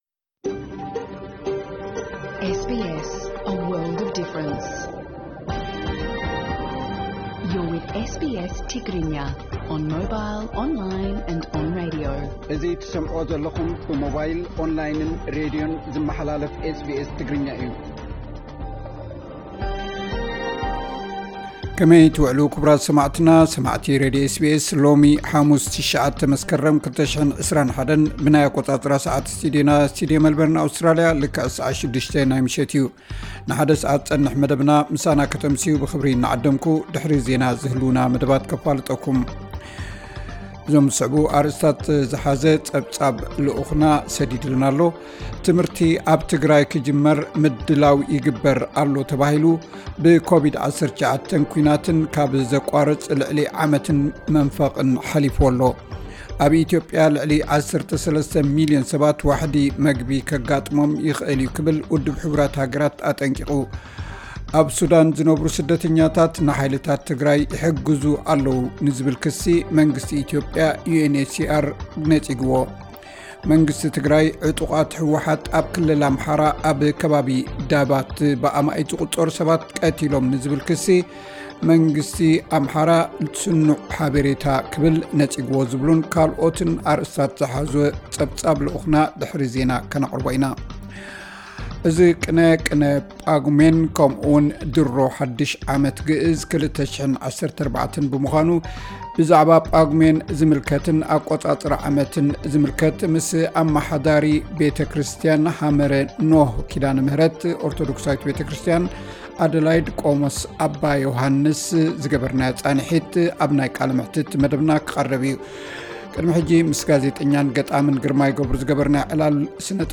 ዕለታዊ ዜና 09 መስከረም 2021 SBS ትግርኛ